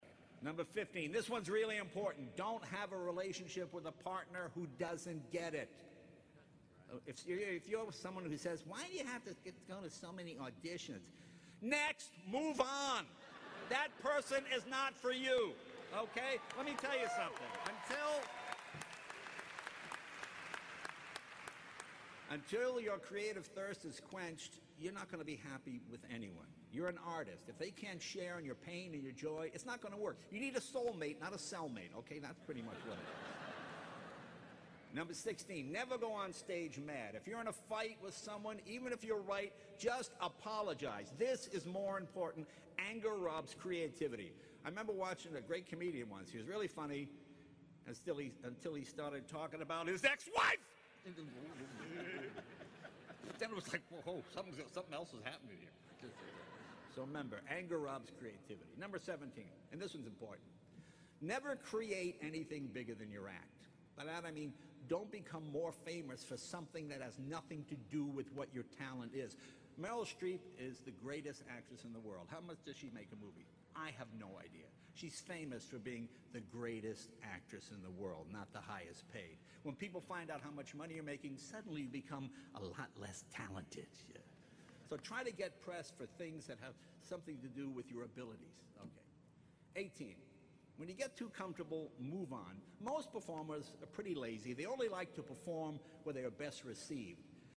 公众人物毕业演讲 第213期:杰雷诺2014爱默生学院(11) 听力文件下载—在线英语听力室